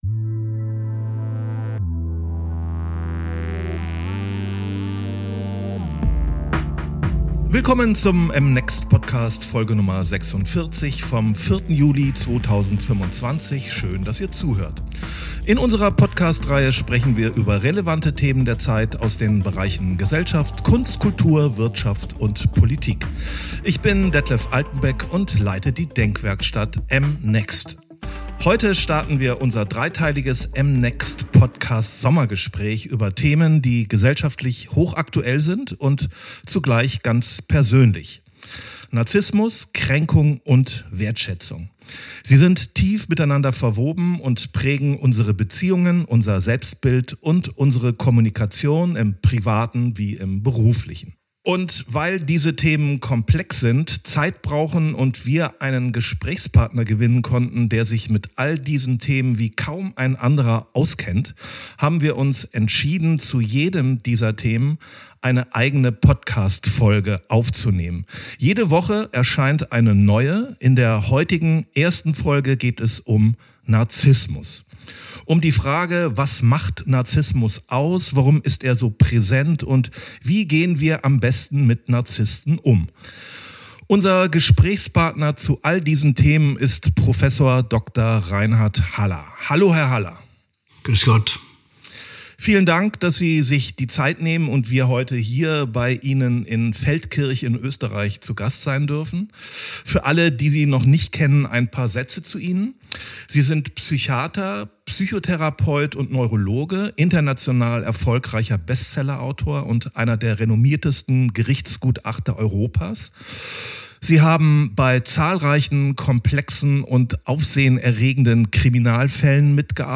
Im Gespräch mit Robert Menasse